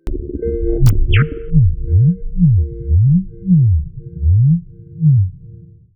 UI_SFX_Pack_61_37.wav